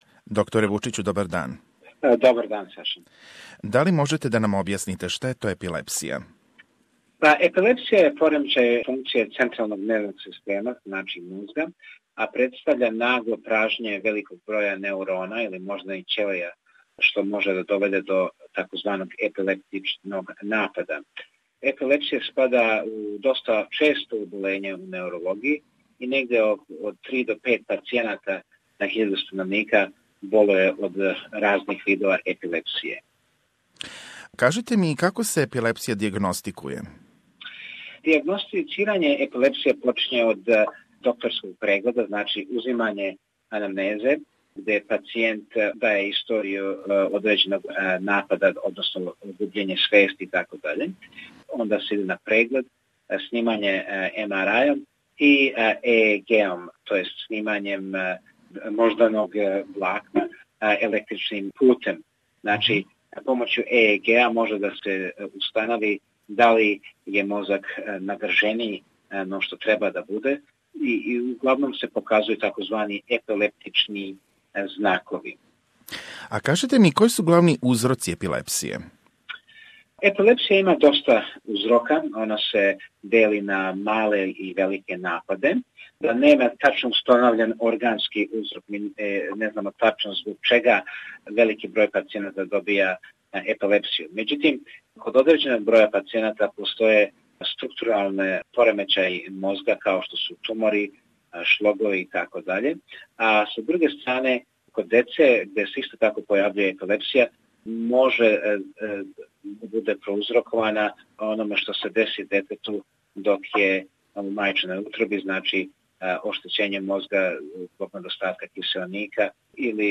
spoke with the neurologist